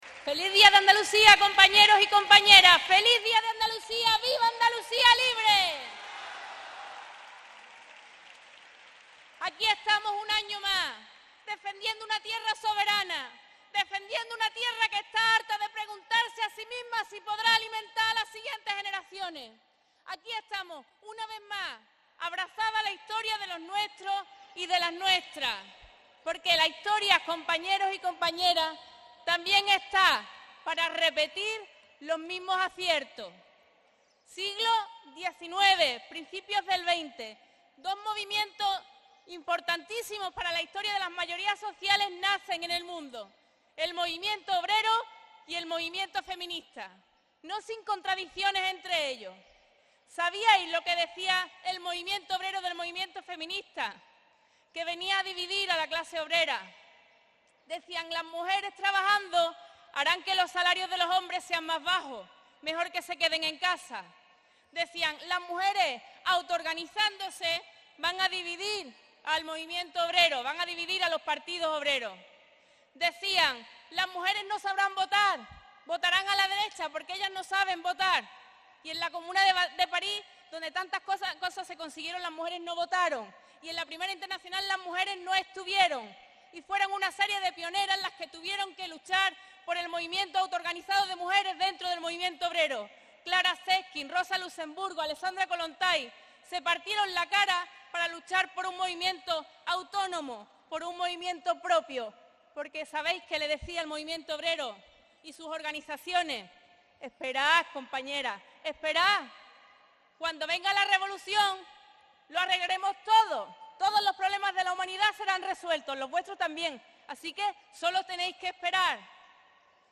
Discurso-Tere-Acto-28F.mp3